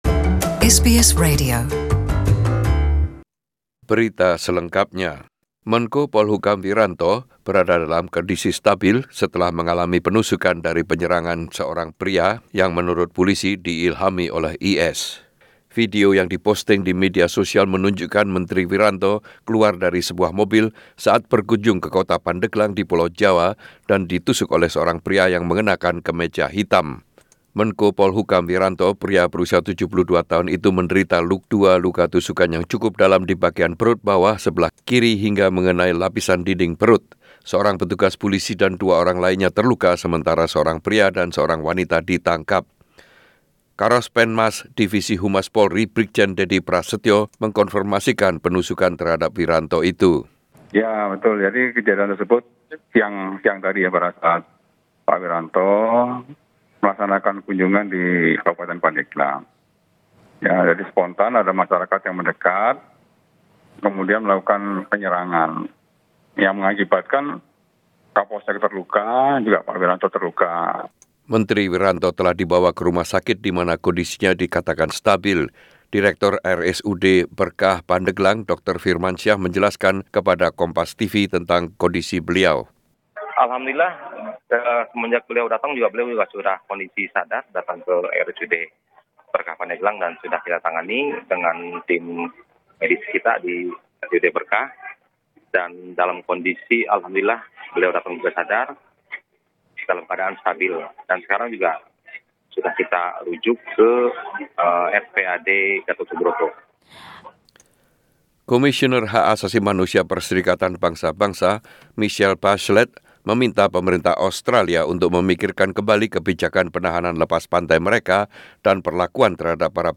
SBS Radio News 11 Oct 2019 in Indonesian